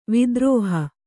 ♪ vidrōha